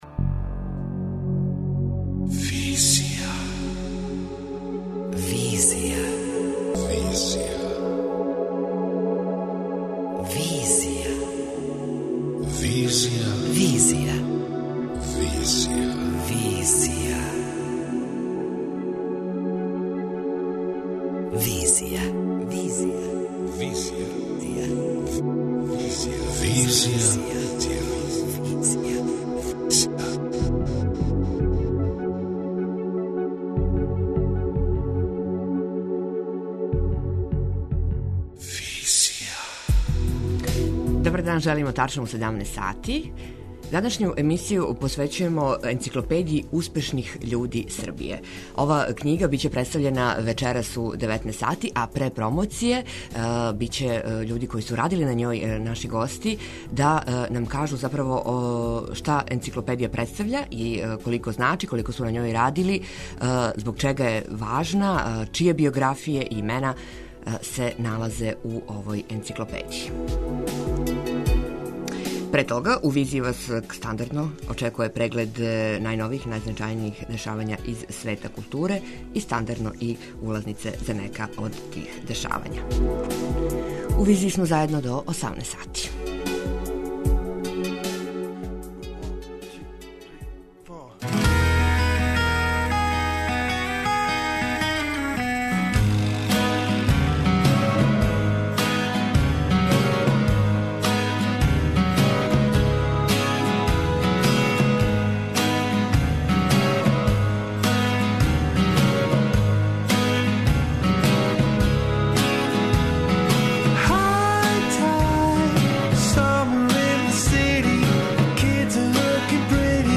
Пред промоцију о овој Енциклопедији разговарамо са представницима тима који су на њој радили.